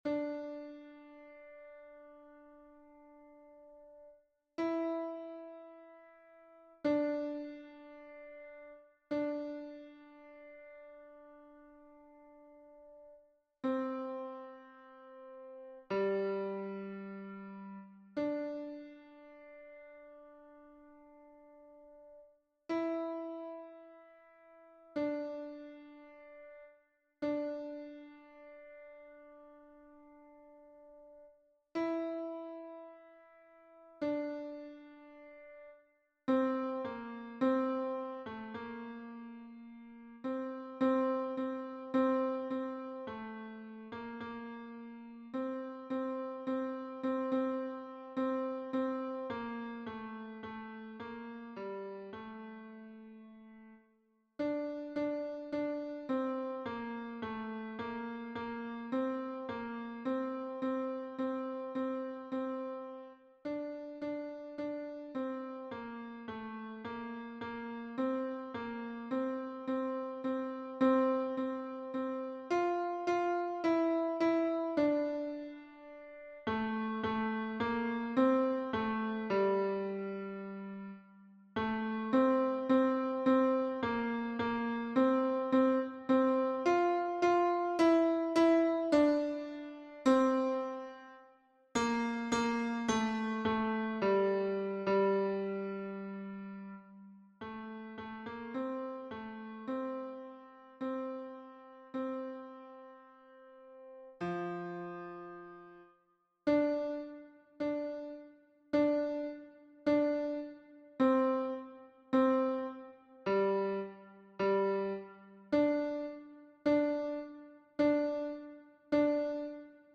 MP3 version piano
Basse